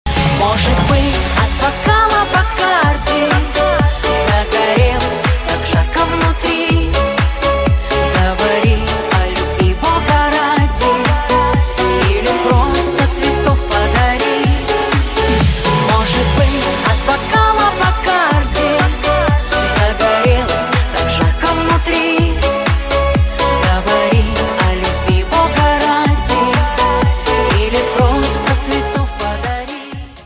• Качество: 320, Stereo
женский вокал
русский шансон